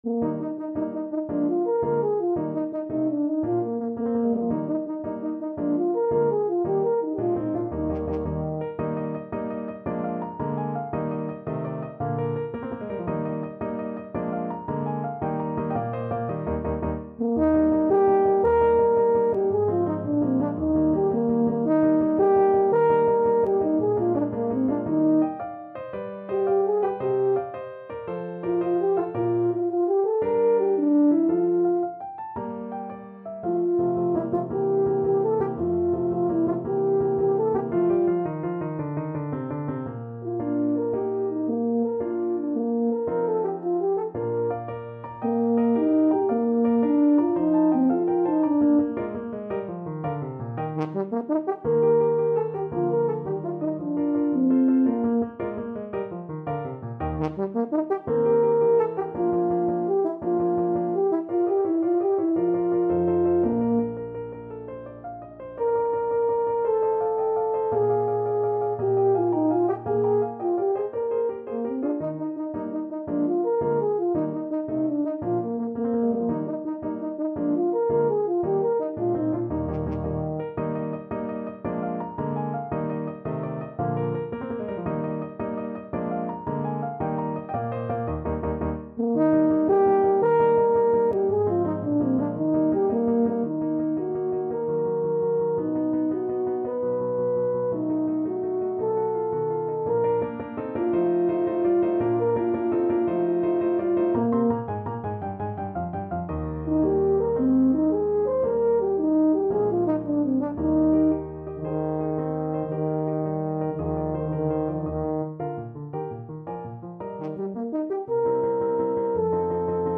6/8 (View more 6/8 Music)
Eb major (Sounding Pitch) (View more Eb major Music for Tenor Horn )
.=112 Allegro vivace (View more music marked Allegro)
Tenor Horn  (View more Advanced Tenor Horn Music)
Classical (View more Classical Tenor Horn Music)